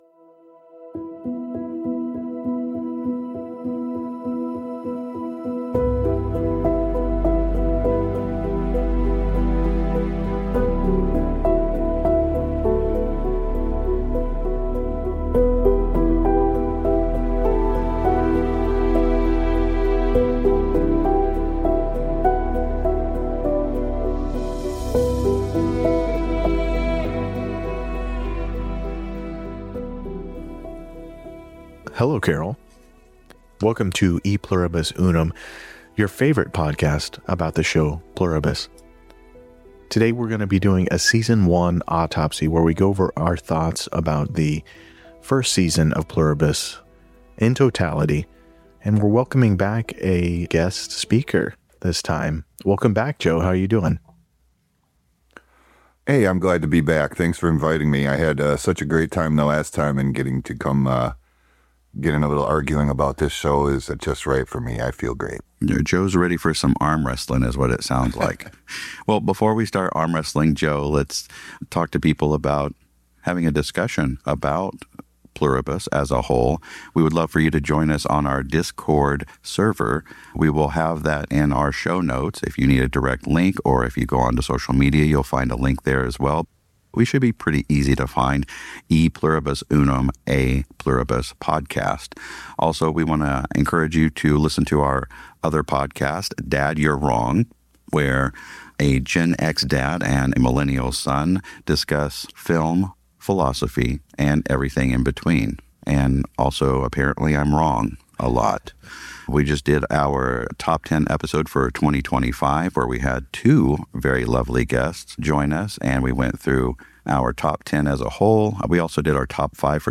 Three hosts, three perspectives, and plenty of disagreement.
Explicit Content Warning: Strong language and full season spoilers throughout.